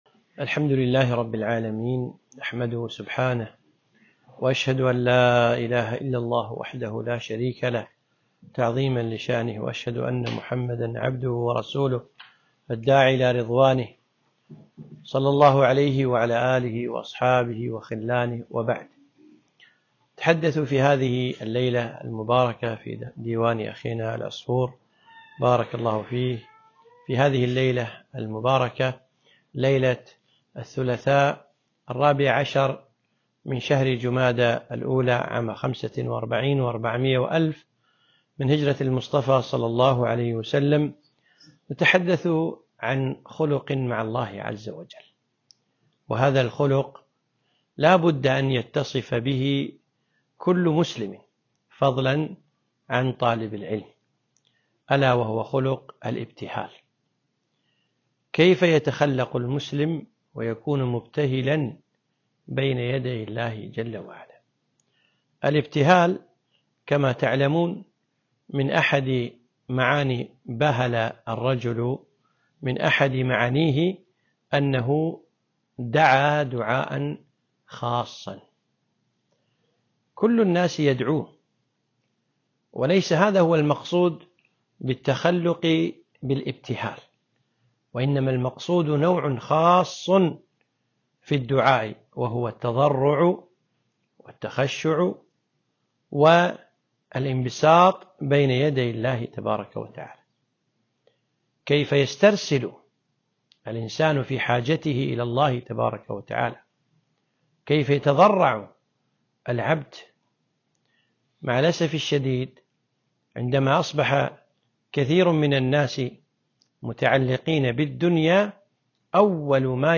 محاضرة - الإبتهال إلى الله